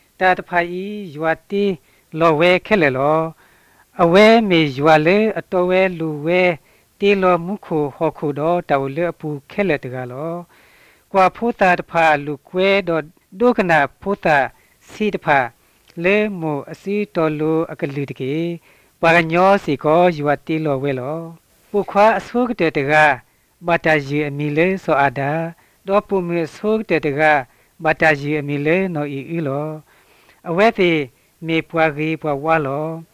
3 April 2017 at 5:09 pm The very strange feature that the last syllable or word of a sentence is almost being sung reminds me of Jingpho spoken in Myanmar.